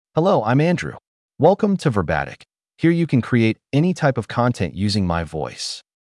MaleEnglish (United States)
Andrew is a male AI voice for English (United States).
Voice sample
Male
Andrew delivers clear pronunciation with authentic United States English intonation, making your content sound professionally produced.